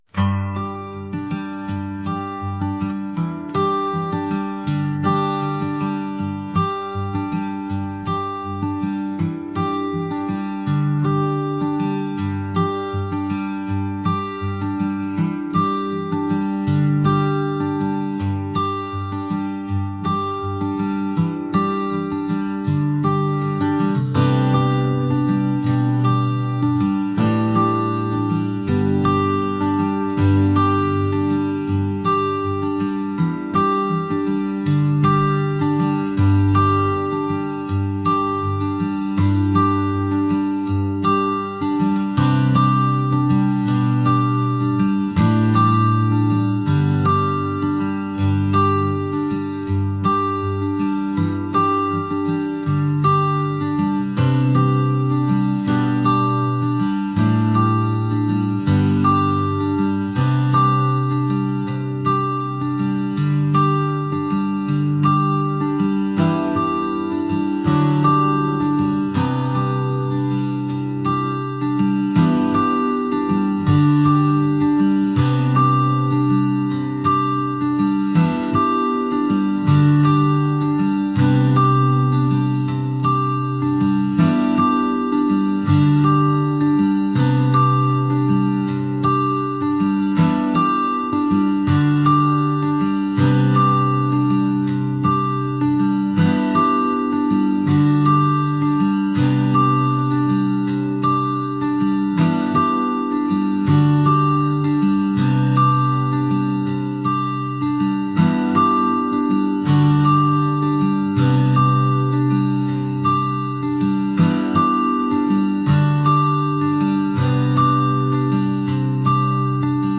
acoustic | folk